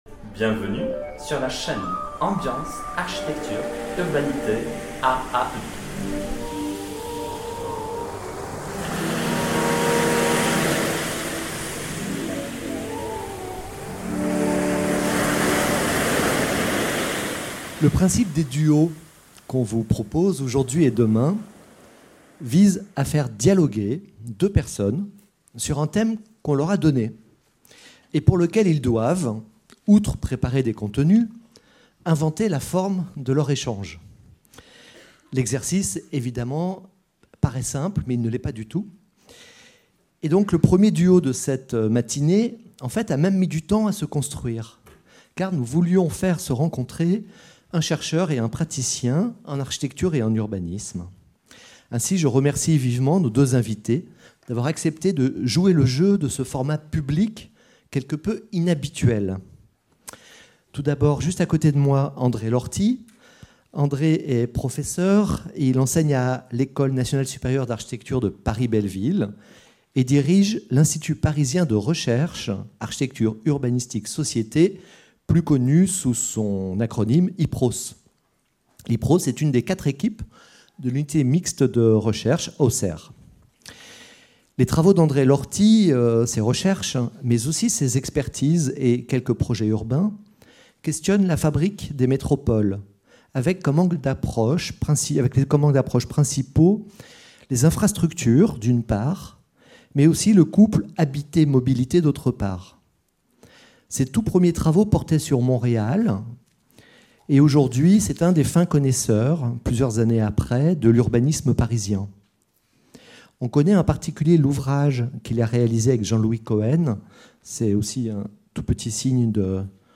Premier duo du Colloque international Expériences sensibles, fabrique et critique des territoires en mutation.